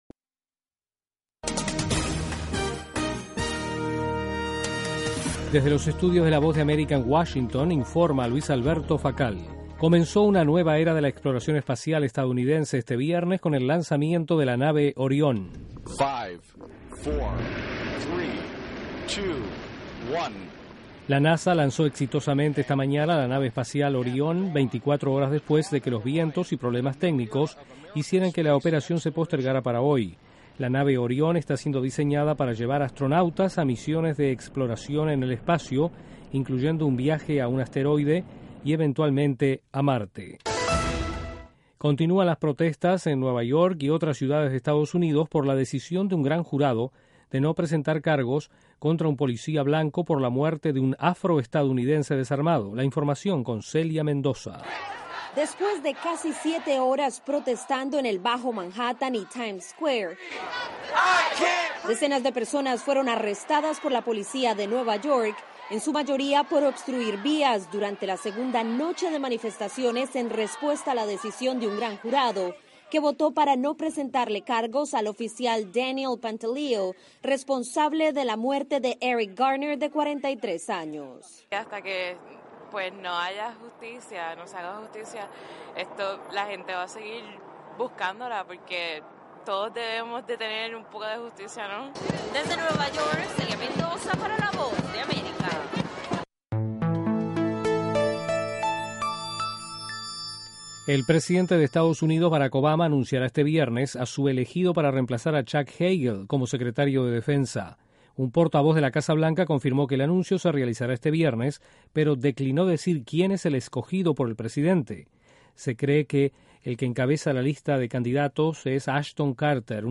En cinco minutos de duración, Informativo VOASAT ofrece un servicio de noticias que se transmite vía satélite desde los estudios de la Voz de América.